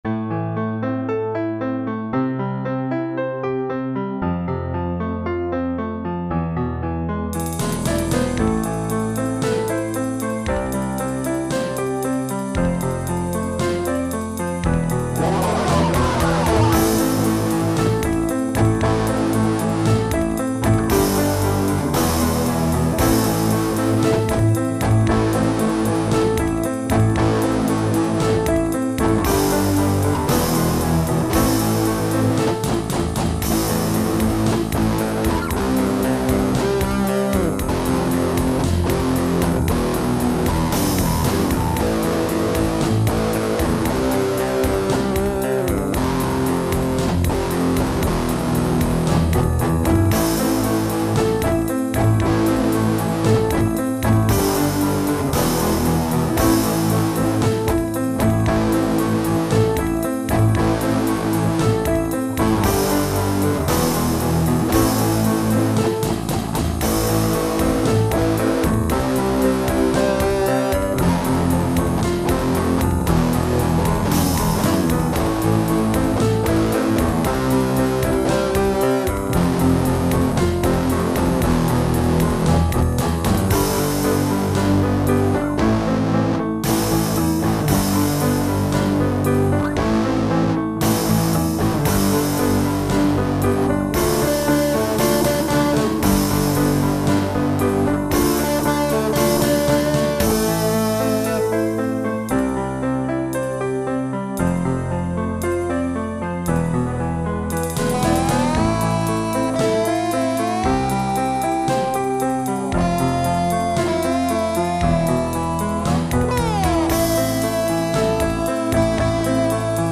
Дэмки на скорую руку это.